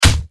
Punch6.wav